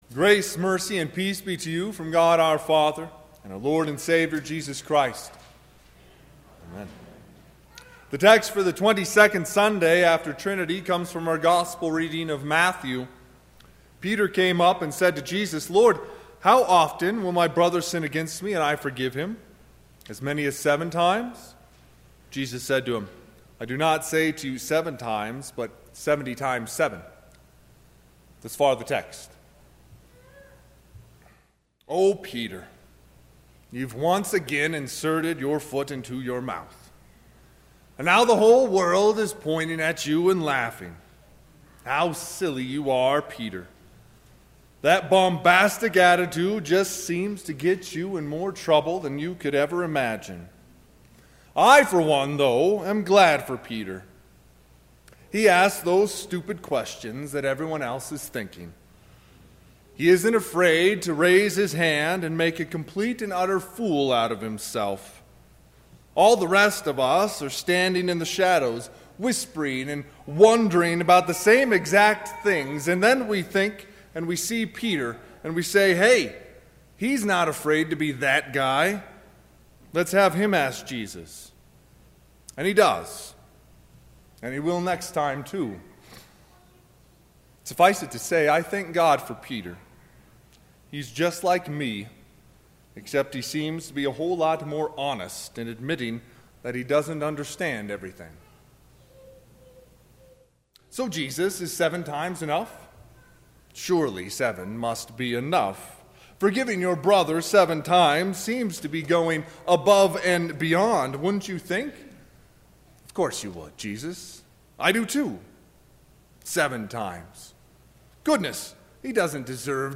Sermon – 11/17/2019